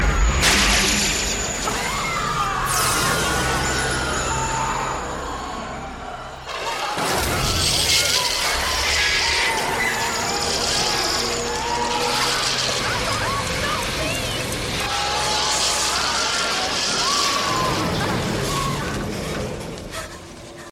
39 Speed - Elevator